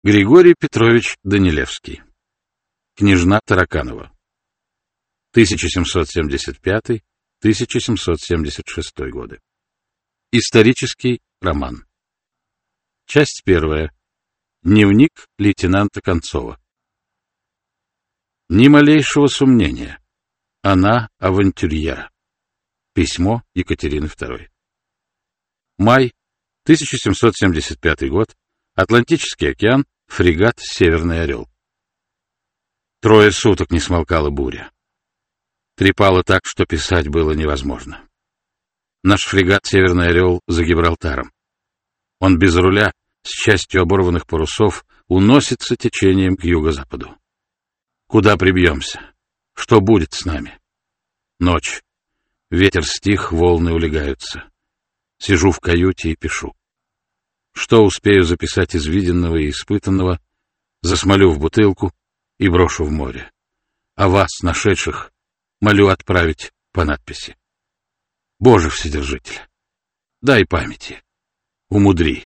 Аудиокнига Княжна Тараканова | Библиотека аудиокниг
Прослушать и бесплатно скачать фрагмент аудиокниги